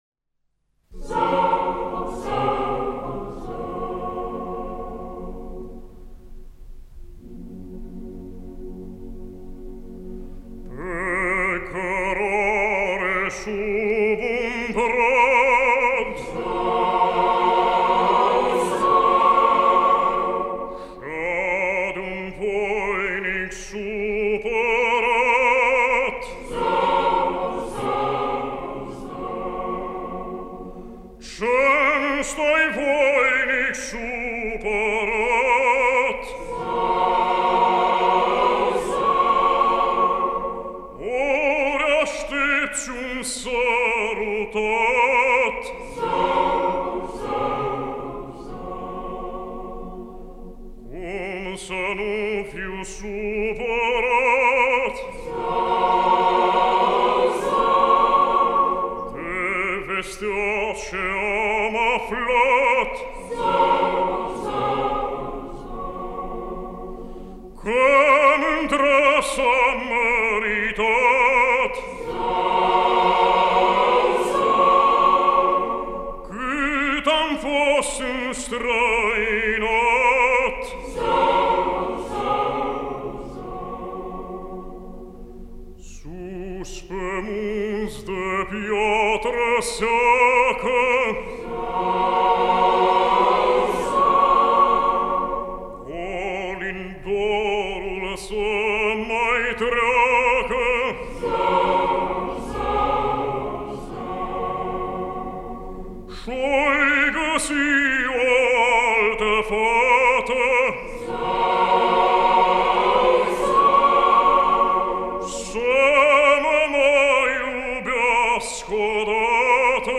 „PE CĂRARE SUB UN BRAD” (D.G. Kiriac) de pe albumul „BIJUTERII CORALE ROMÂNESTI (II)” interpretat de Corul Naţional de Cameră MADRIGAL – dirij. MARIN CONSTANTIN, solist Nicolae Herlea.